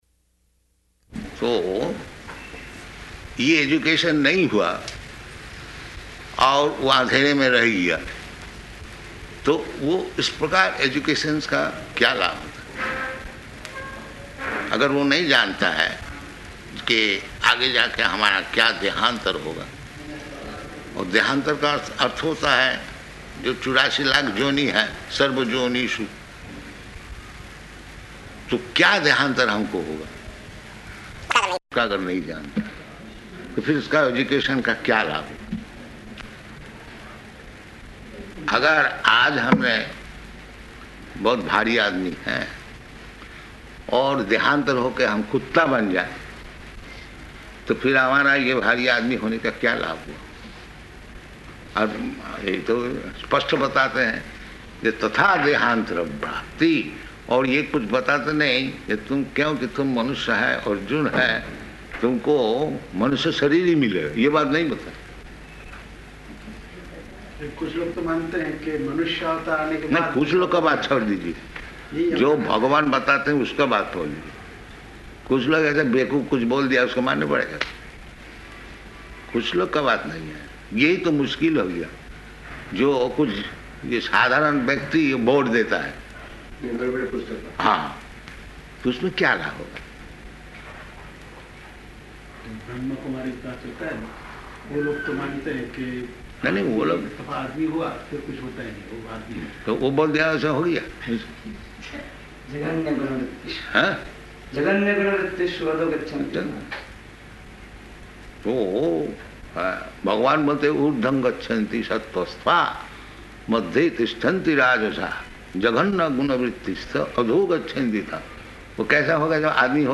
Room Conversation in [Hindi--final proofreading pending]
Type: Conversation
Location: Ahmedabad